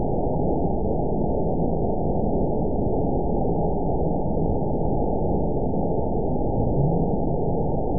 event 922728 date 03/22/25 time 23:39:34 GMT (2 months, 3 weeks ago) score 9.23 location TSS-AB02 detected by nrw target species NRW annotations +NRW Spectrogram: Frequency (kHz) vs. Time (s) audio not available .wav